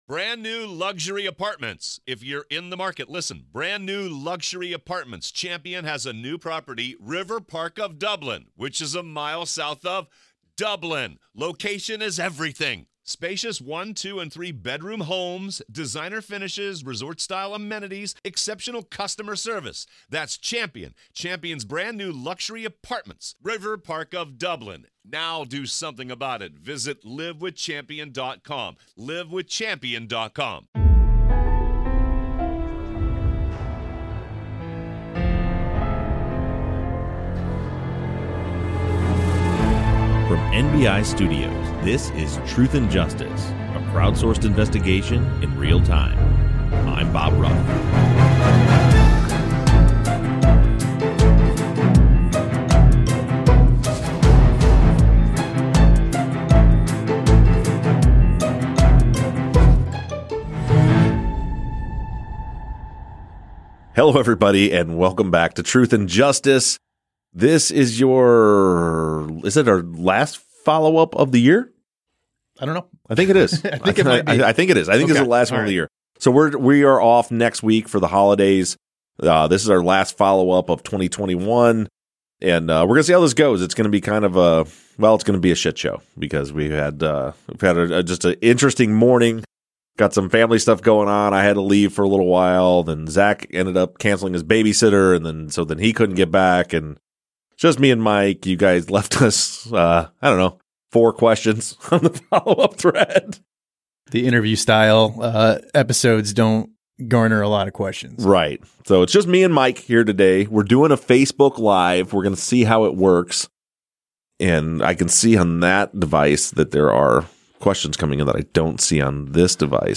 Facebook Live Follow-Up